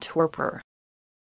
Audio Pronunciation of Torpor
Click to hear the pronunciation of torpor.